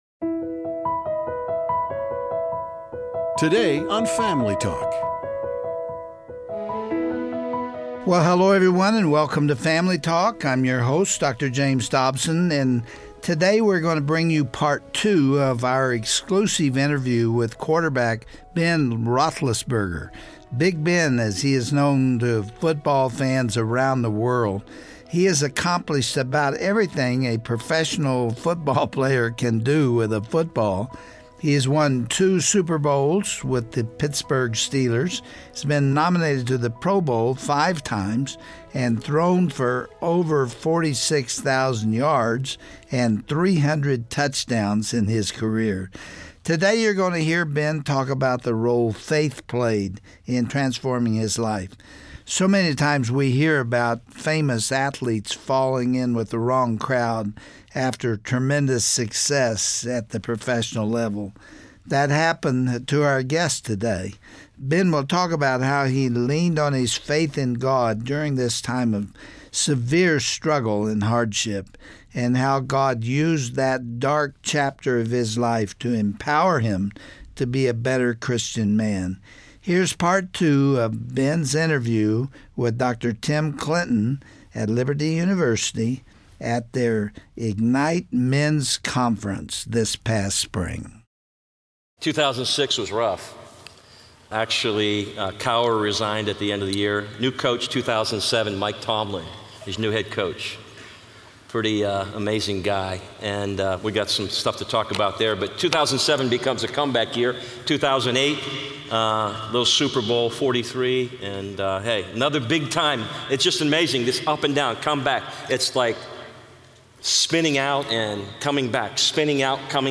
Today Family Talk is excited to bring an exclusive interview with two-time Super Bowl winning quarterback Ben Roethlisberger.